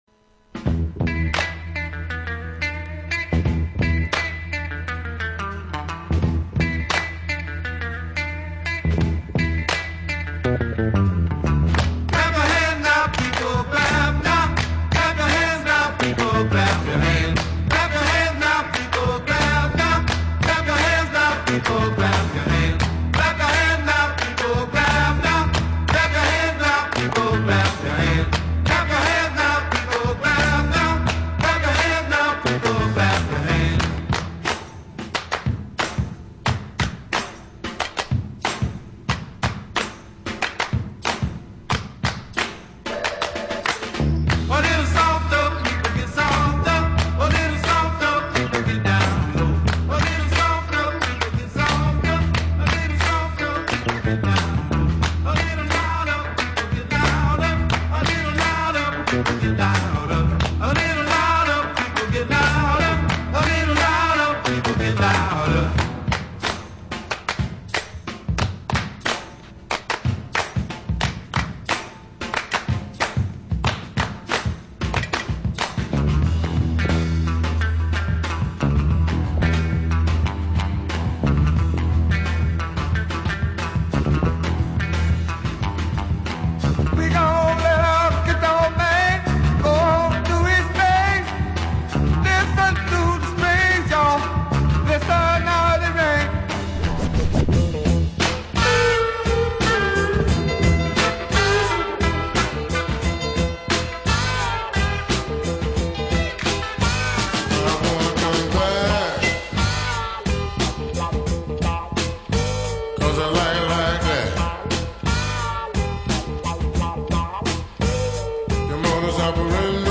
Ein Mix-Tape meiner momentanen Funk-Favoriten.